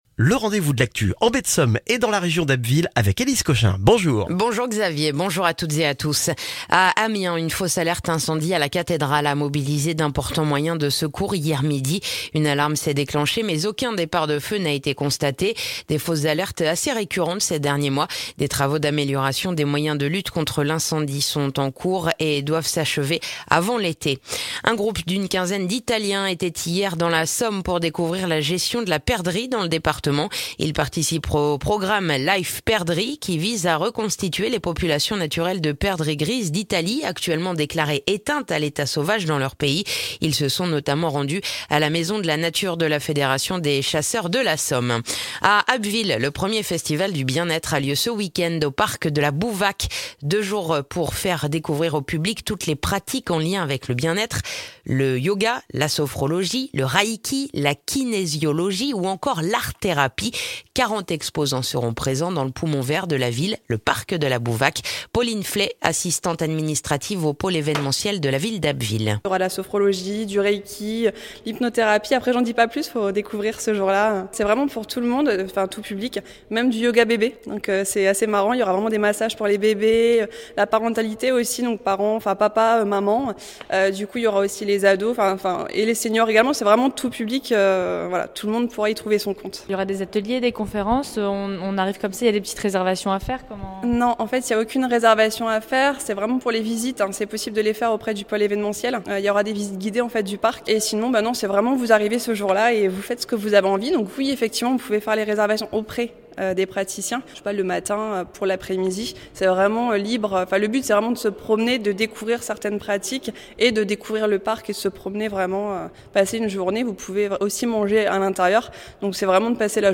Le journal du vendredi 13 mai en Baie de Somme et dans la région d'Abbeville